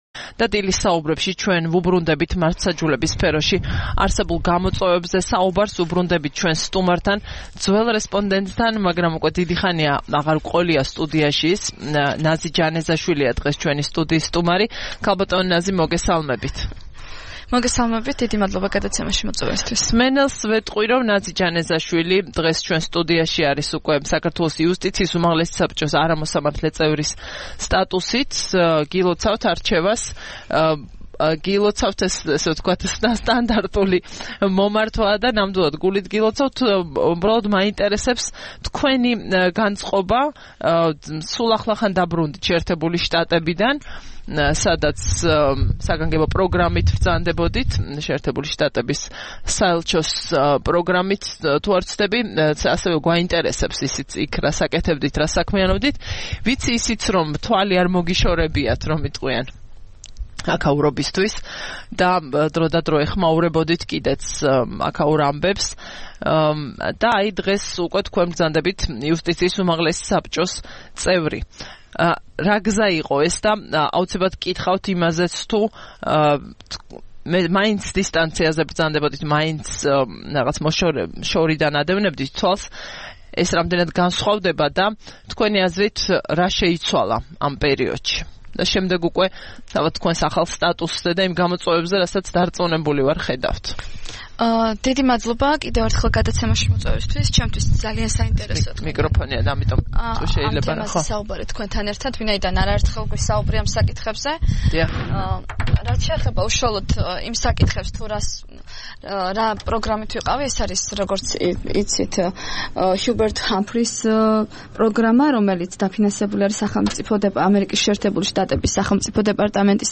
23 ივნისს რადიო თავისუფლების "დილის საუბრების" სტუმარი იყო ნაზი ჯანეზაშვილი, იუსტიიის უმაღლესი საბჭოს წევრი.